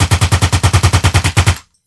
M-60.WAV